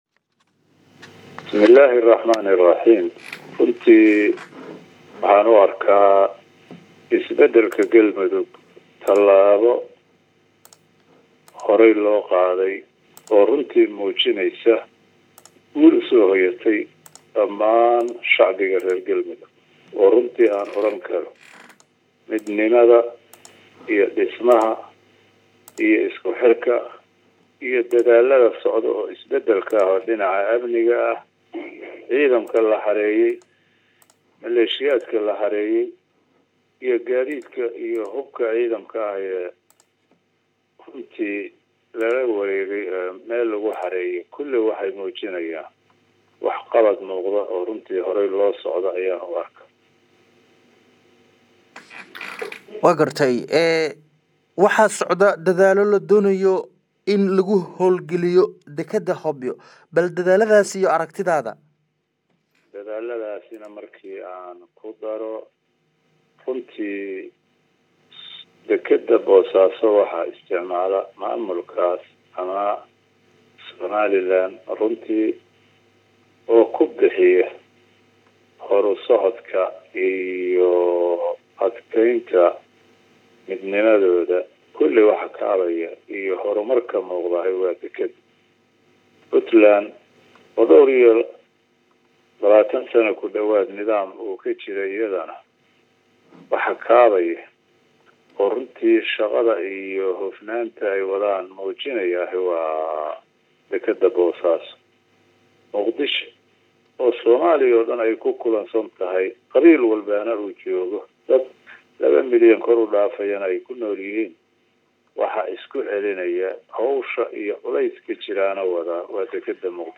waraysi-Axmed-baasto.mp3